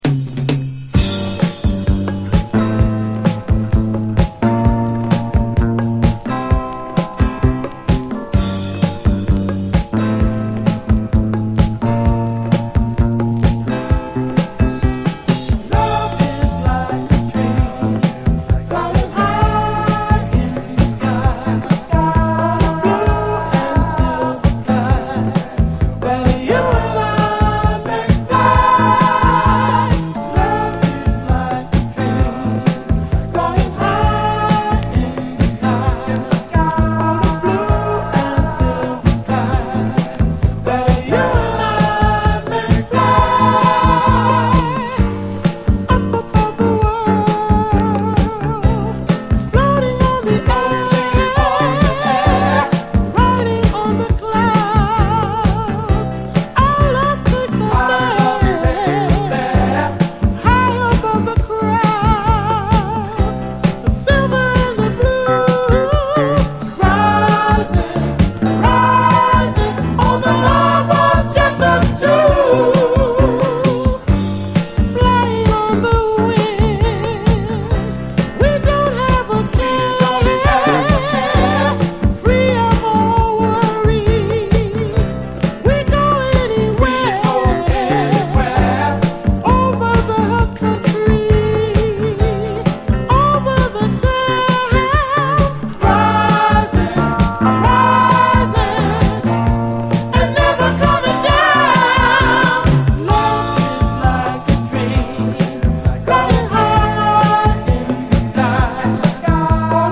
SOUL, 70's～ SOUL, 7INCH
巧みなコーラス・ワークを駆使した、流麗なミディアム・ダンサー！